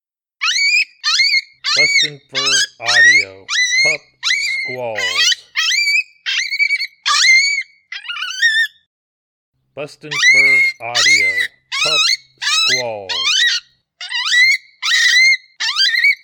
BFA Pup Squalls
Young Coyote Pup in distress, lots of high pitch squalling in this one.
BFA Pup Squalls Sample.mp3